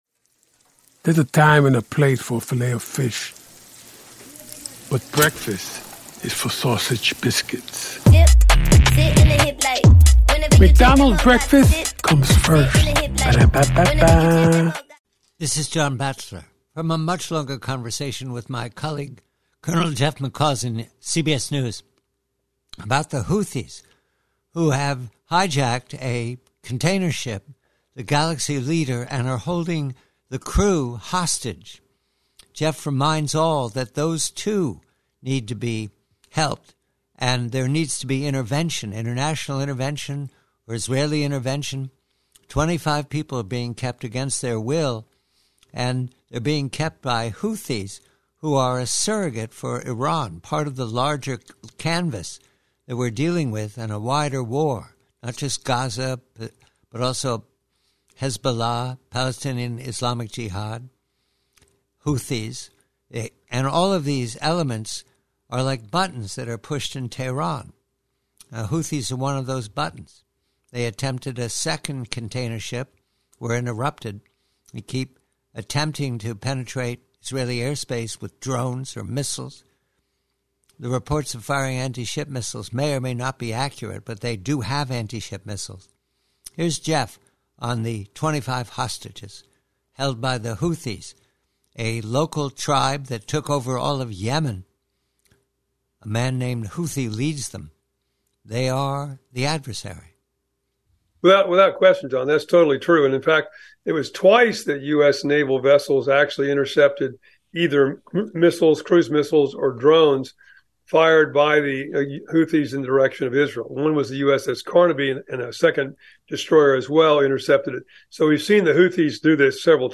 PREVIEW: From a longer conversation with Colonel Jeff McCausland of CBS News, confirming that the Houthis of Yemen, surrogates for Tehran, also hold hostages from the 25 man crew of the hijacked Galaxy Leader..